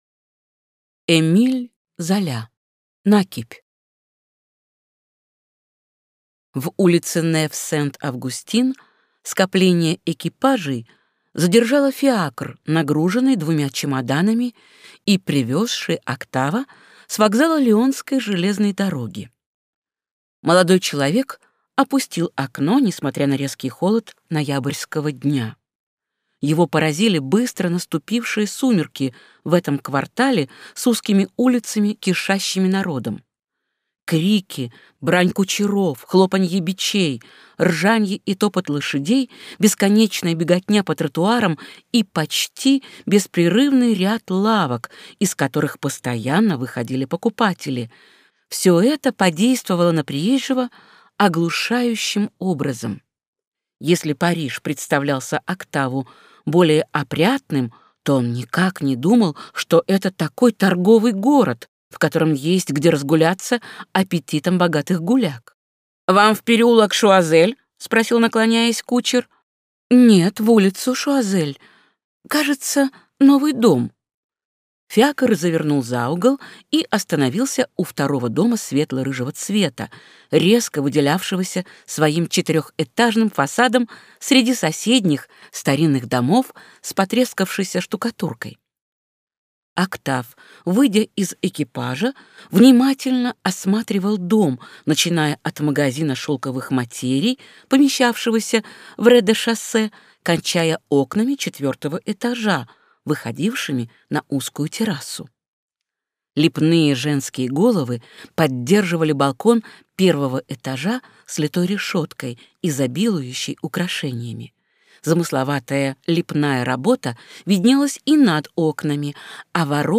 Аудиокнига Накипь | Библиотека аудиокниг